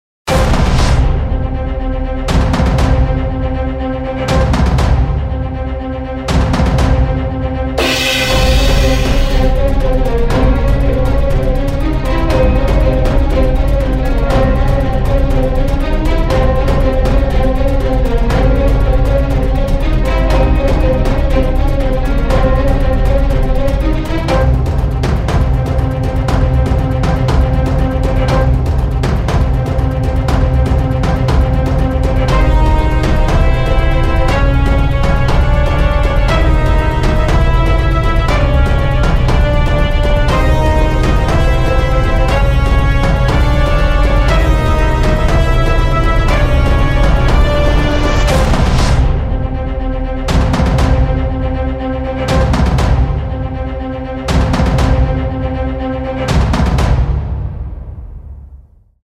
■ ストリングスによる“逃げ場のない緊張感”
低音〜中音域を中心に不穏で重たいコード進行を採用。
明るさを徹底的に排除することで「希望が見えない空気感」を演出しています。
シネマティックでおなじみのDamage系サウンドを使用し一撃ごとの重み＝絶対王者の強さを表現。
・過剰に詰め込まないリズム
・緊張状態を維持したままループ